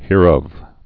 (hîr-ŭv, -ŏv)